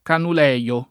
Canuleio [ kanul $L o ]